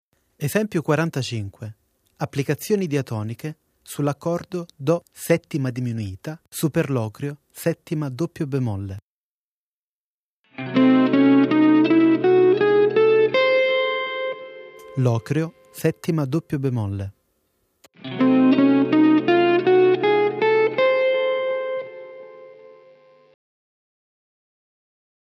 Soluzioni modali su C°7
Nome del modo: Superlocrio bb7